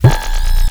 sci-fi_spark_electric_device_active_03.wav